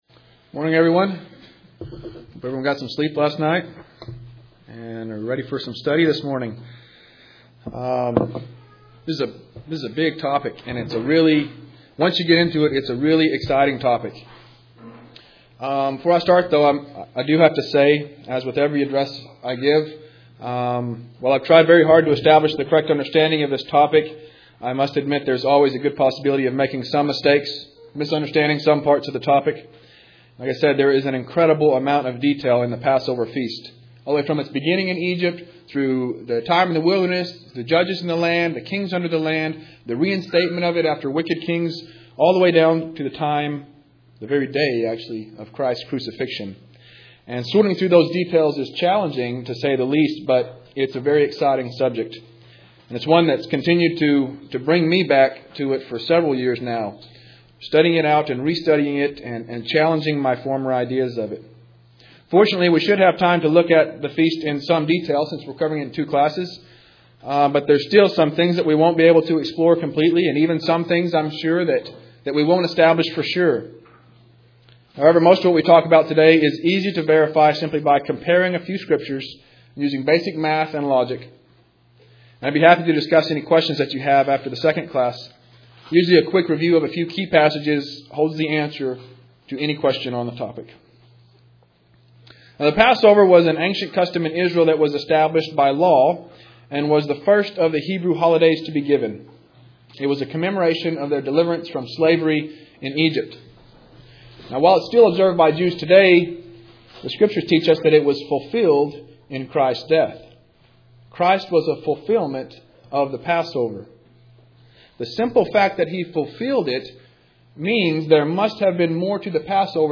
The Goldthwaite ecclesia held its 7th annual November Gathering this year.